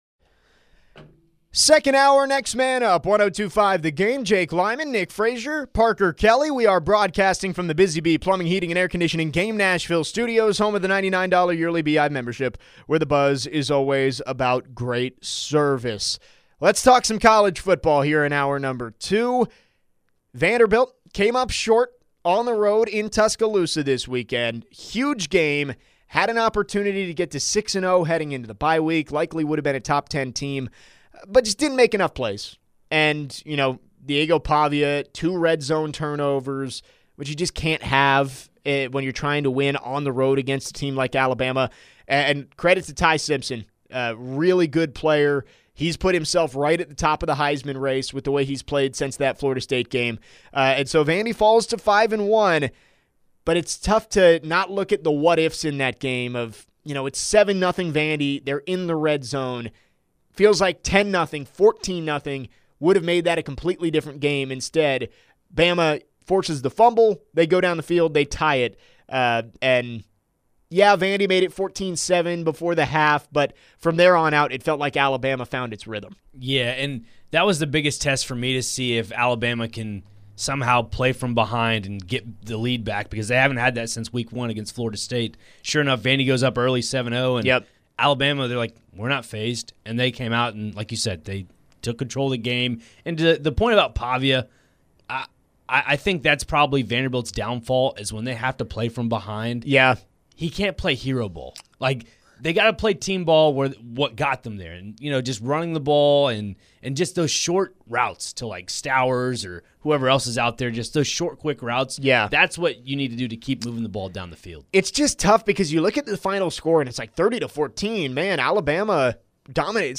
The guys recap and share their thoughts on Vanderbilt's loss to Alabama this past Saturday. Additionally, what impact does this have on Vanderbilt's playoff hopes, and which teams are contenders for the College Football Playoff?